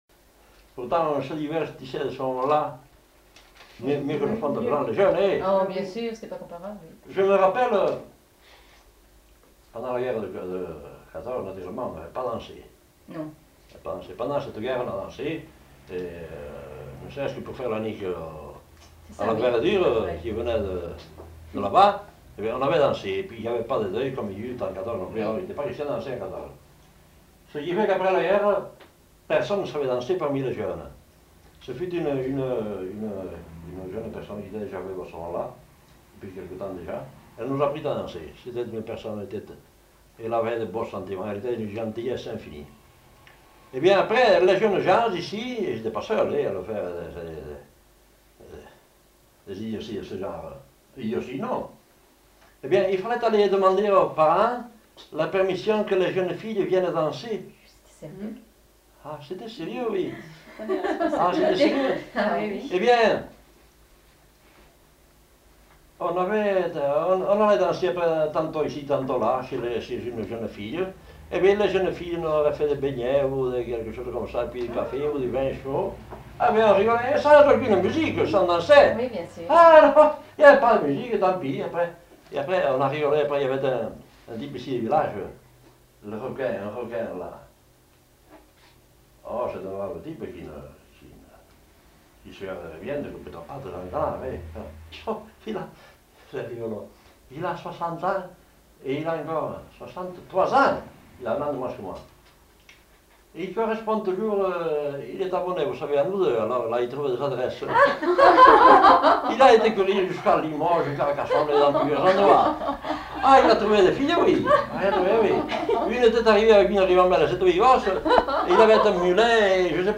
Aire culturelle : Bigorre
Genre : témoignage thématique